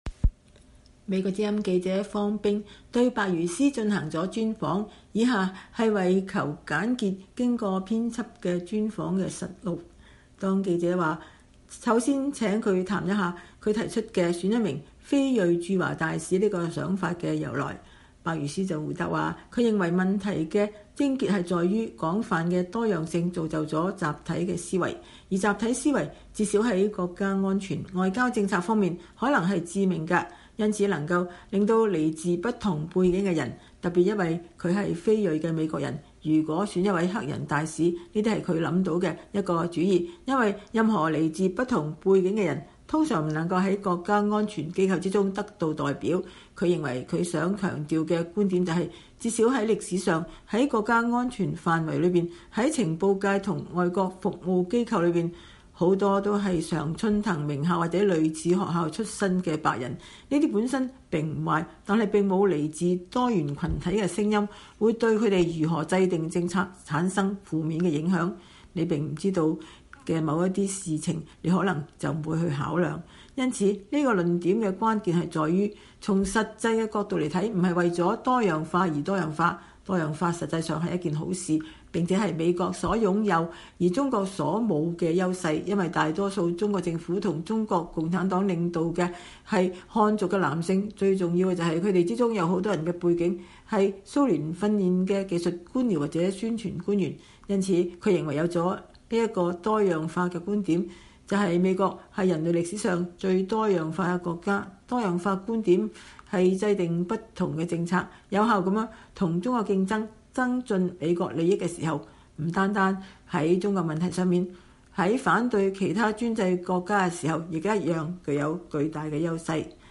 專訪：體現美國多樣化戰略優勢，拜登應任命黑人駐華大使